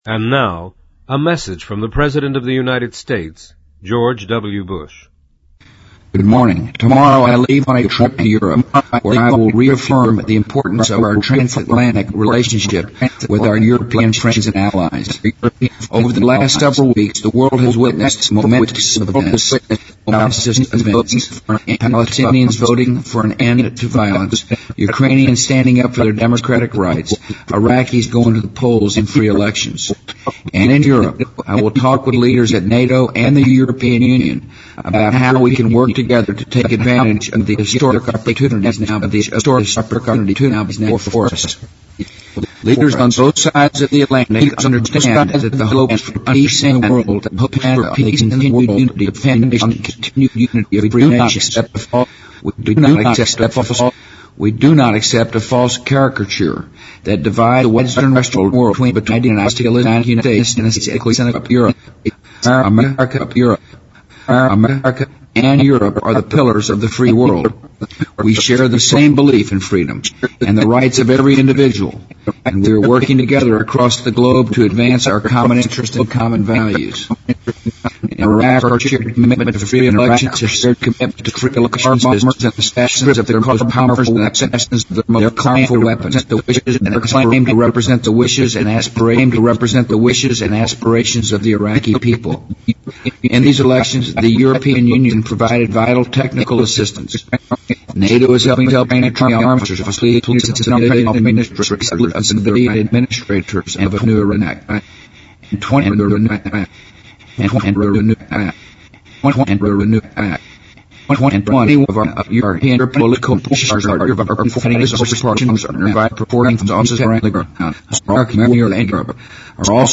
President Bush-2005-02-19电台演说 听力文件下载—在线英语听力室